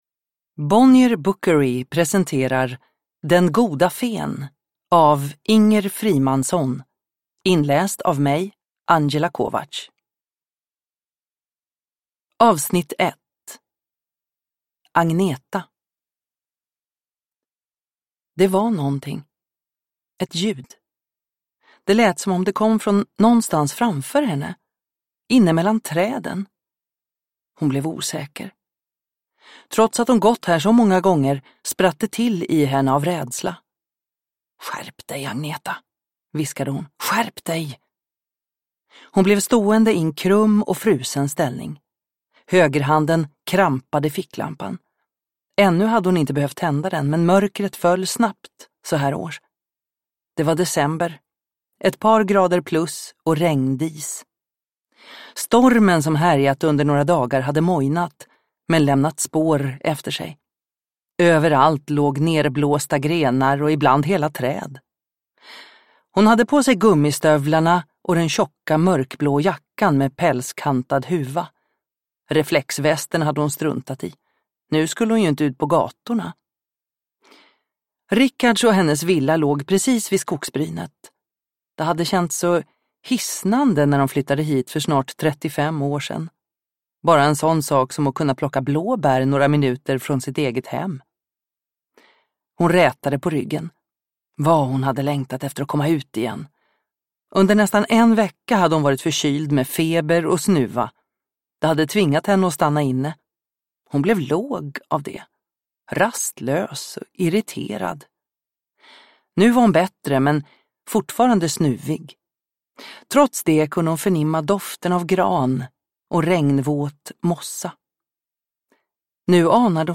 Den goda fen E1 (ljudbok) av Inger Frimansson